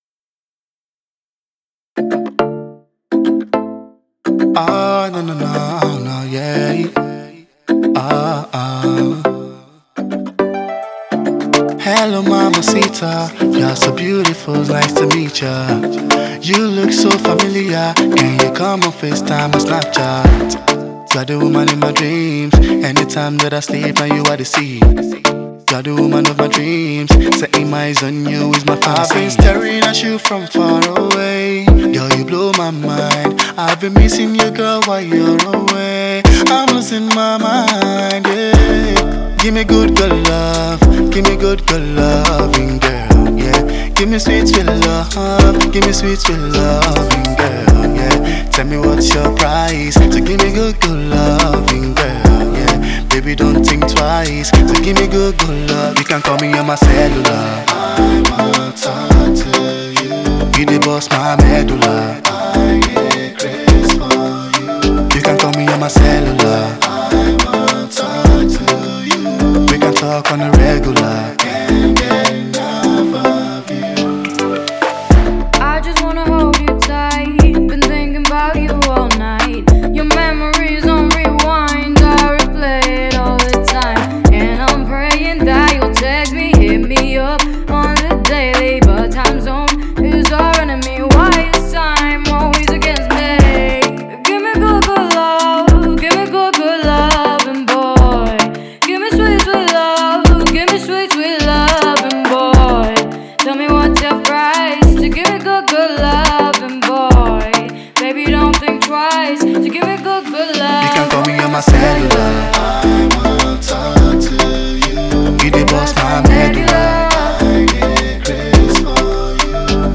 love tune